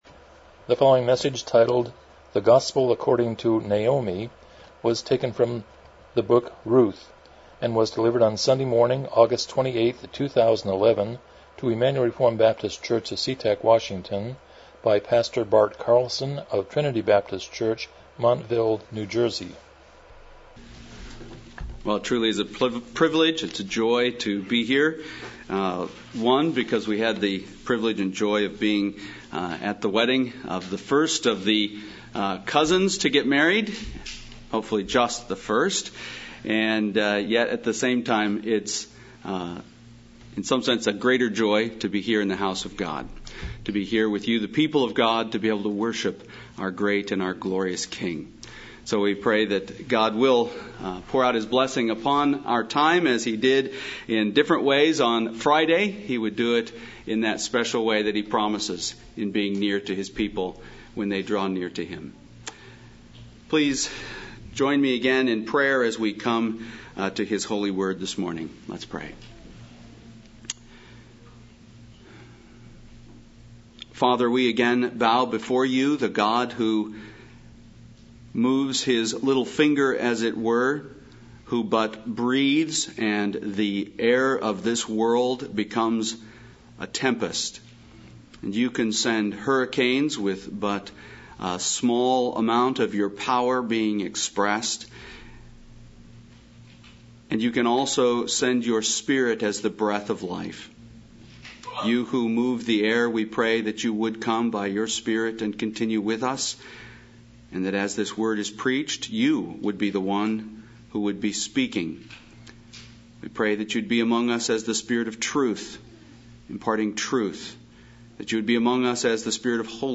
Miscellaneous Service Type: Morning Worship « 126 Chapter 26.1-2